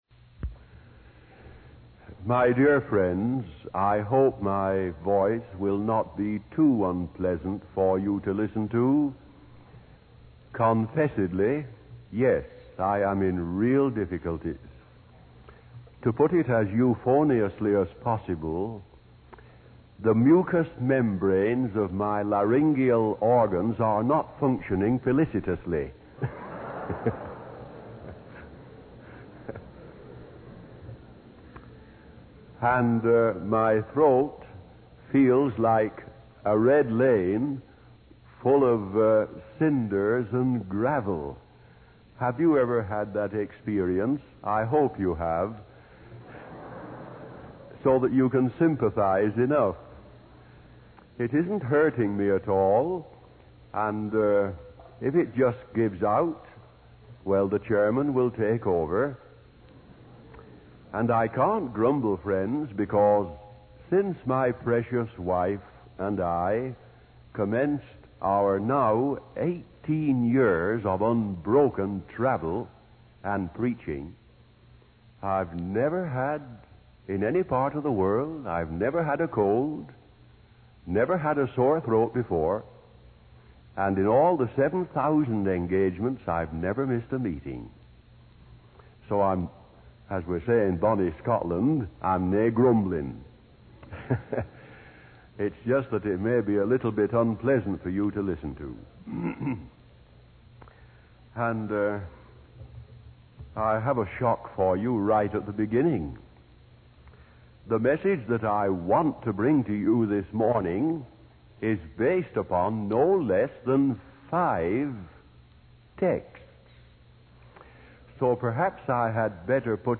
In this sermon, the preacher begins by assuring the audience that despite having five texts, the sermon will not be excessively long. The sermon focuses on the Holy Spirit and its role in convicting the world of sin, righteousness, and judgment.